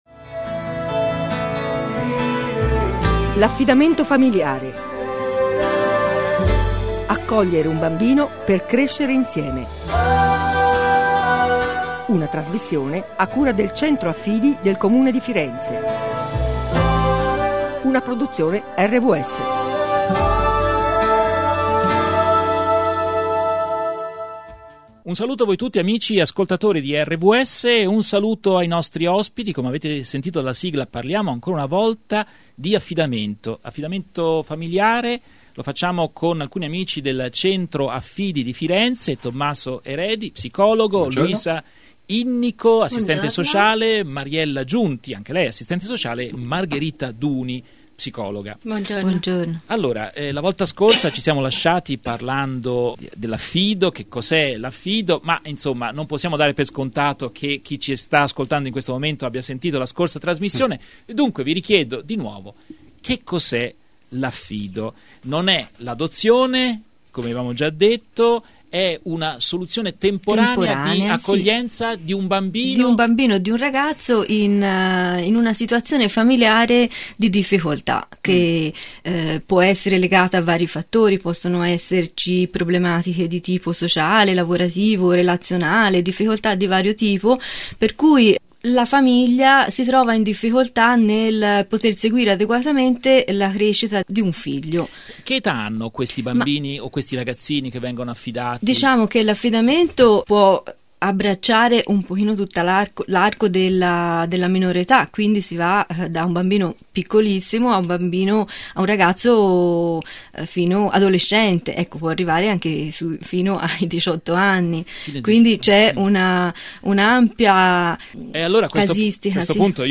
Seconda trasmissione di un ciclo che si propone di illustrare i vari aspetti dell’affidamento familiare: occasioni, sfide e problemi. A parlarne in studio operatori